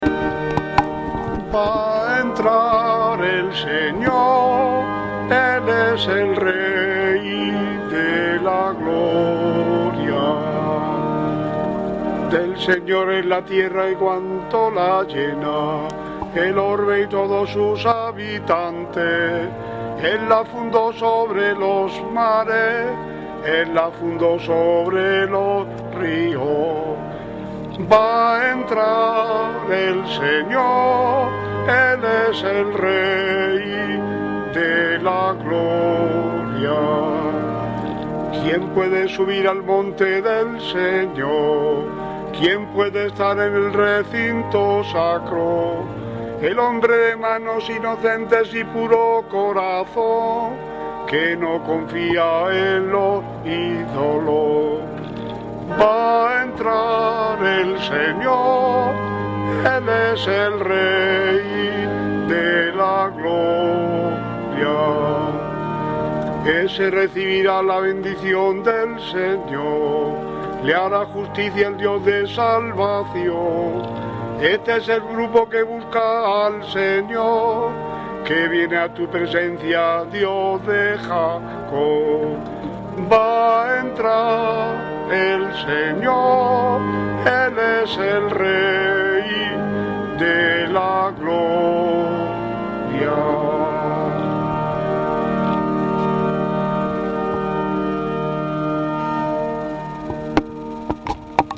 Salmo Responsorial [1.625 KB]
A-Domingo-04-Salmo-Adviento.mp3